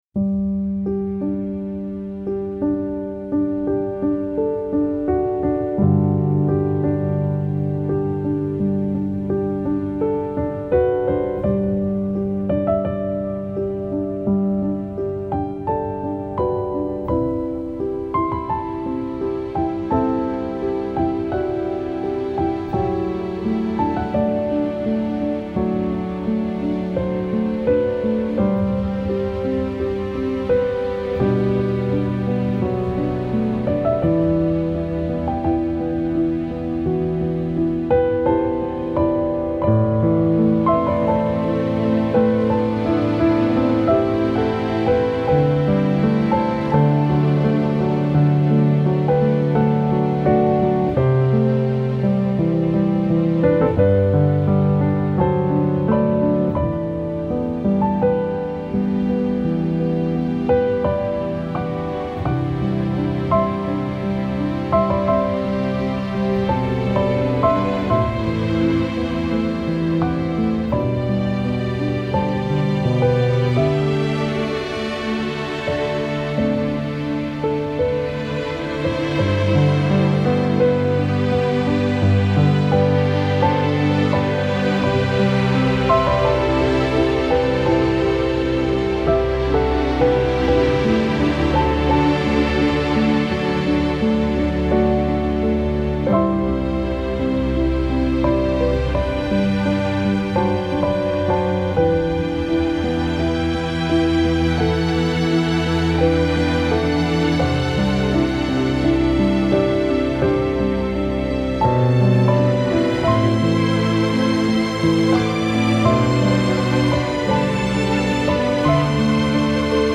سبک آرامش بخش , عصر جدید , موسیقی بی کلام
موسیقی بی کلام آرامبخش